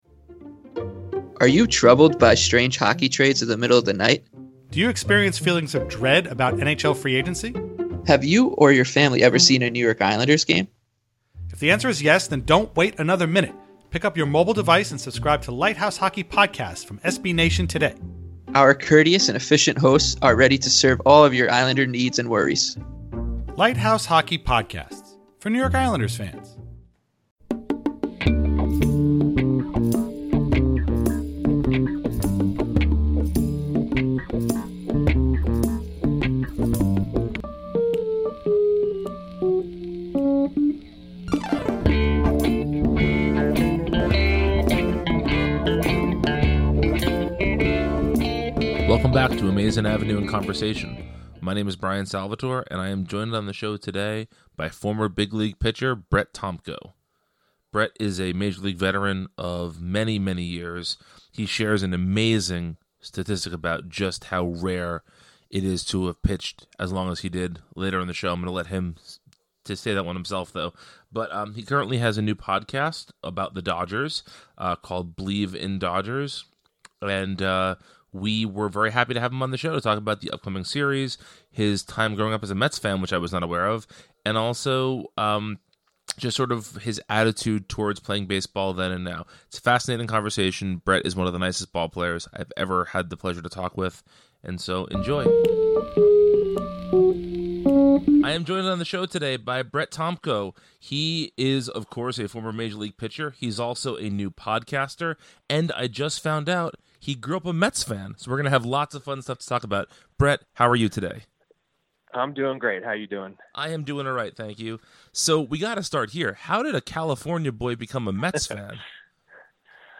Welcome to Amazin’ Avenue in Conversation, a new podcast from Amazin’ Avenue where we invite interesting people on the show to talk about themselves, the Mets, and more.